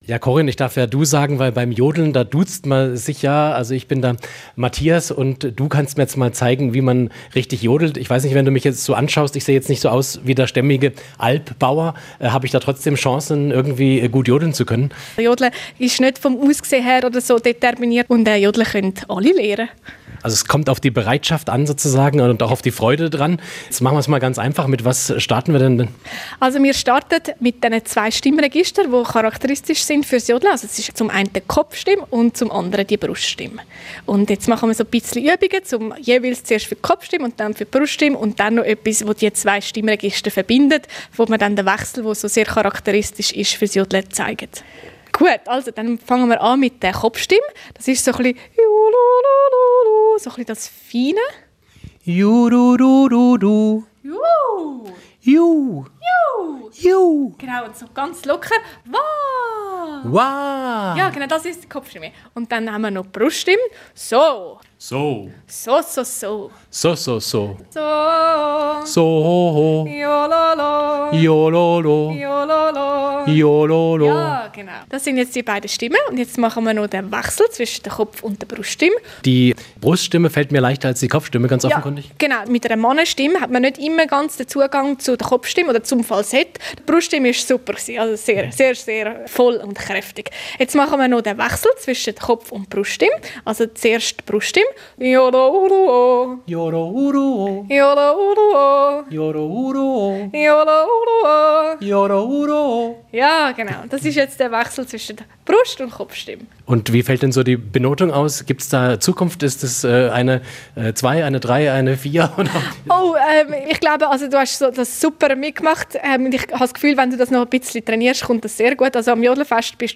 Richtiger Mix aus Kopf- und Bruststimme
Beim Jodeln gibt es zwei charakteristische Stimmregister - die hohe Kopf- und die tiefe Bruststimme. Typisch ist der Wechsel, der fließende Übergang von der einen zur anderen Stimmlage.
Mit der Jodel-Kopfstimme habe ich zu kämpfen